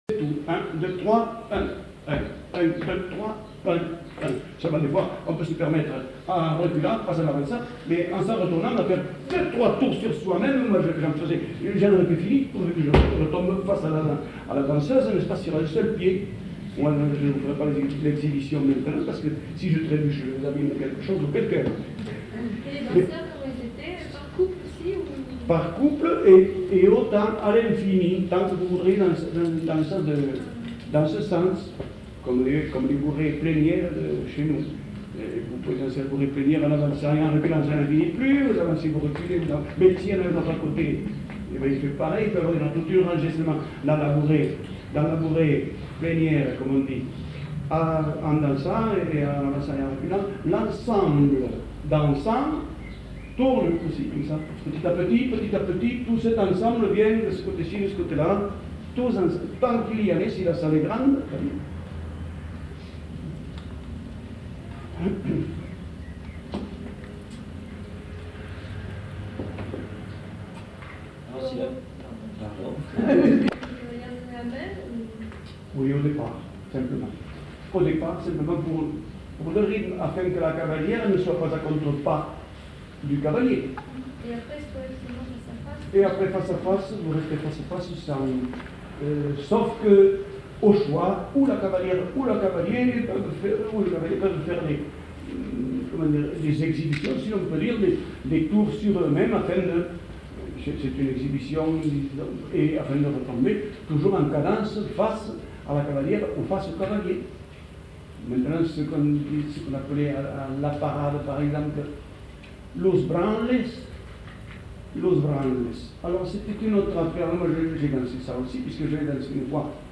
Lieu : Foulayronnes
Genre : témoignage thématique